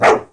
dog.wav